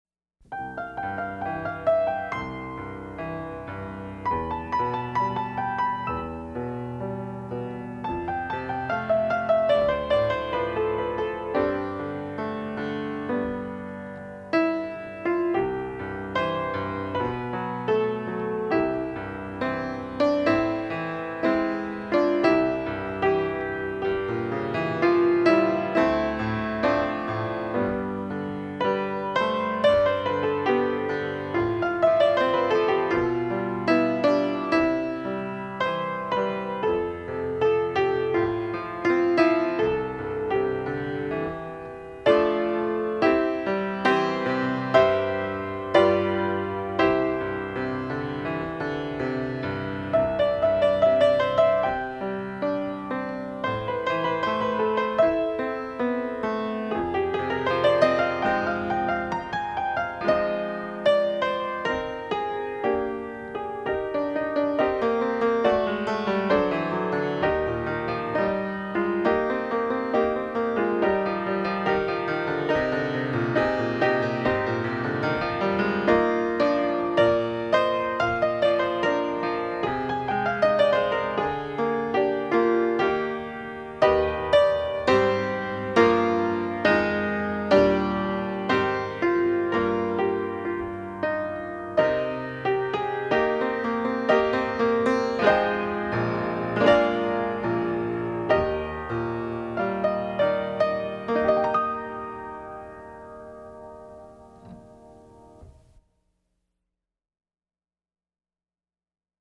PIANO SOLO Christmas, Traditional English Carol
DIGITAL SHEET MUSIC - PIANO SOLO